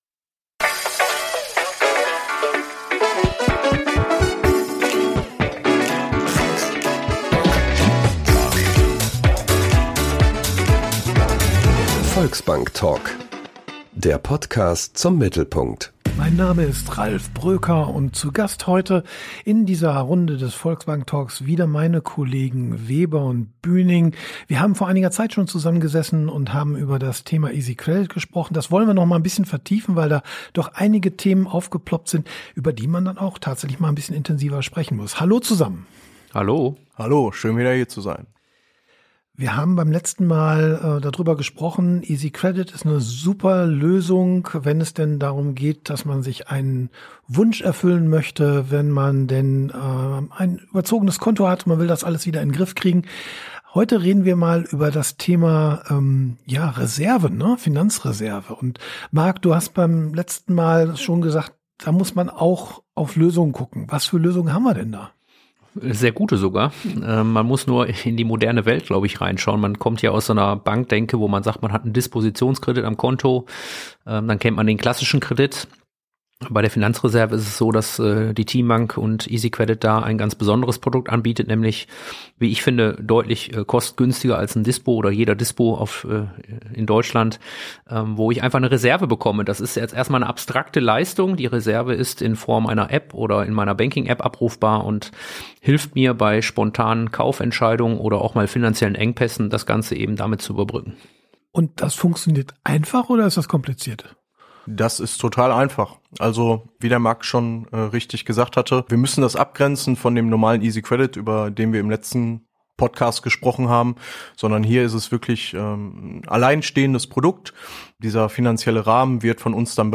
Das MittelPunkt-Gespräch mit Menschen aus der Region zu Themen der Region